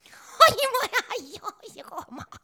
SOMETHING.wav